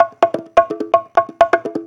Conga Loop 128 BPM (5).wav